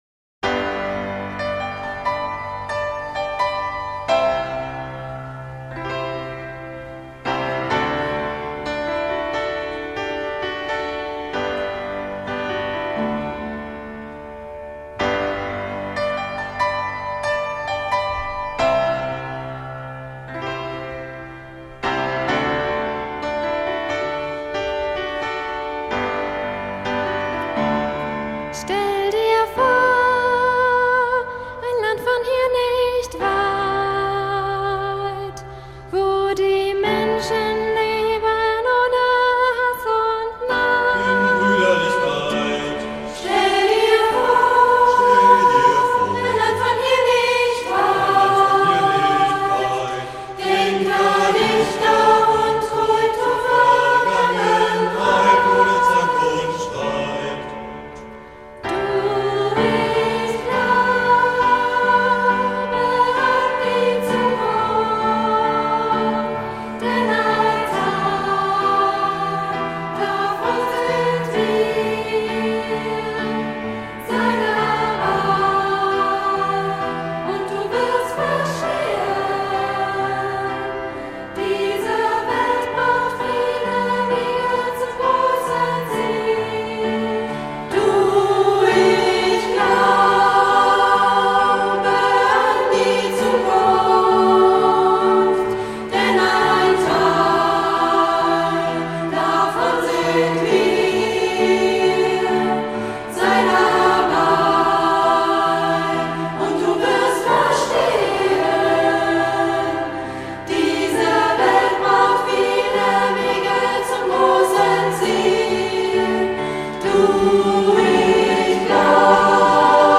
gesungen vom Hölty-Chor
Der Chortitel Unite, Europe, Unite ist so angelegt, dass viele Elemente europäischer Einigung erfasst werden: So beginnt ein Sologesang, der die Individualität des Einzelnen betont.
Die Vielfalt Europas geht aber deutlich über den Gedankenaustausch nur zweier Partner hinaus, deshalb füllt sich langsam das durch Sopran und Bass geschaffene Angebot mit dem Alt und Tenor.
Die Wiederholungen zum Schluss des Liedes sollen deutlich den Aufforderungscharakter des Liedes betonen und sind deshalb bewusst gleich gehalten worden.